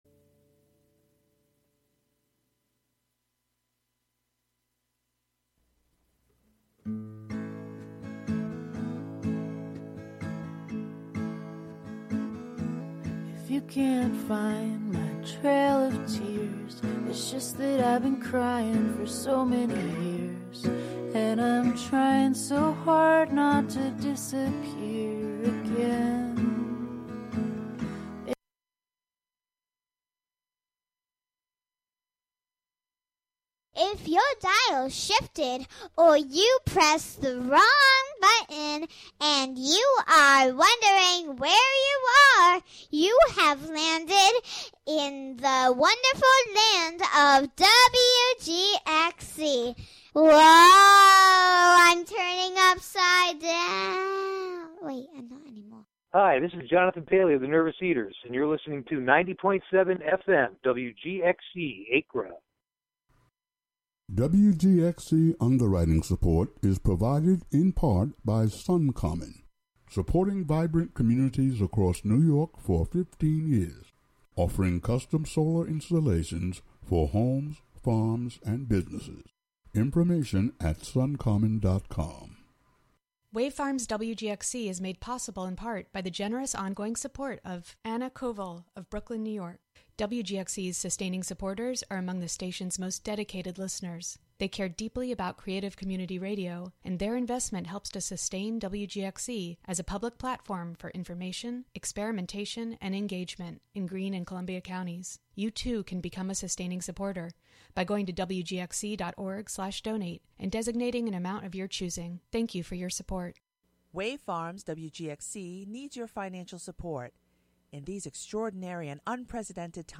Monthly excursions into music, soundscape, audio document and spoken word, inspired by the wide world of performance. Live from Ulster County.